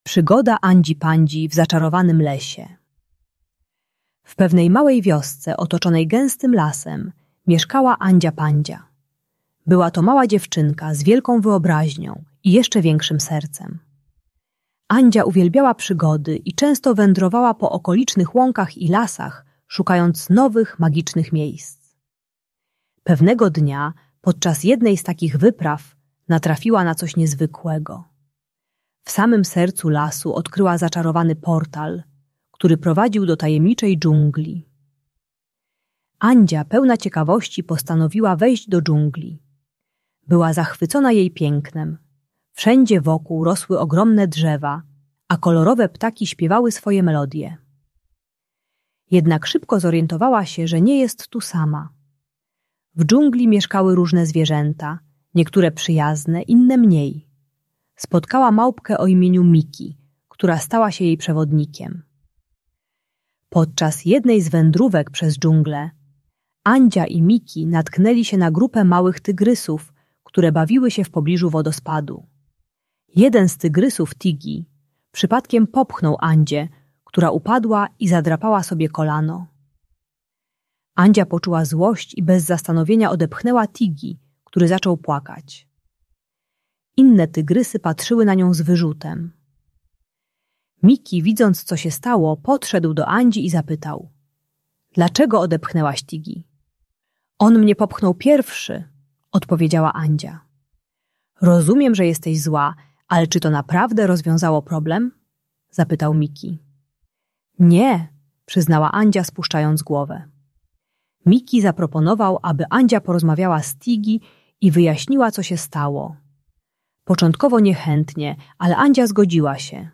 Uczy techniki "najpierw porozmawiaj" - zamiast oddawać, dziecko uczy się wyrażać słowami co czuje i prosić o pomoc dorosłego. Audiobajka o złości i agresji.